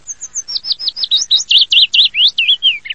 Phylloscopus trochilus
Lui_Grosso_Phylloscopus_trochilus.mp3